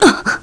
Maria-Vox_Damage_kr_01.wav